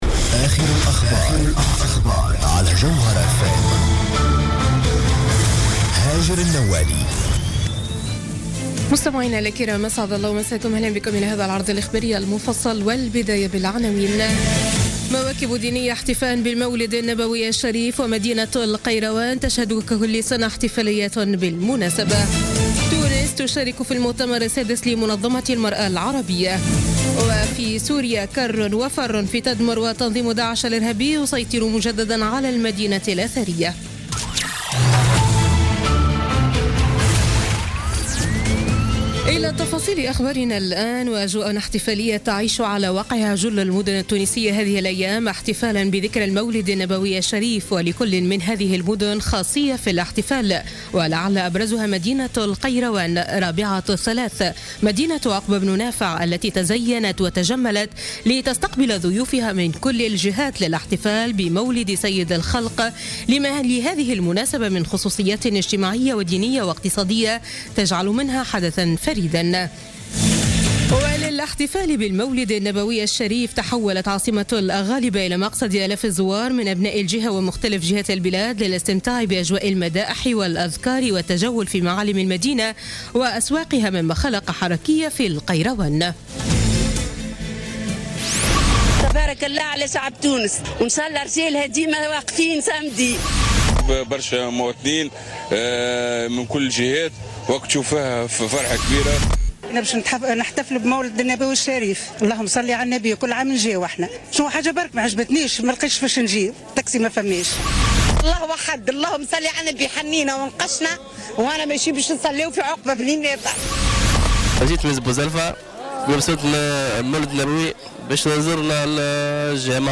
نشرة أخبار منتصف الليل ليوم الإثنين 12 ديسمبر 2016